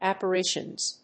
/ˌæpɝˈɪʃʌnz(米国英語), ˌæpɜ:ˈɪʃʌnz(英国英語)/
フリガナアッパリシャンズ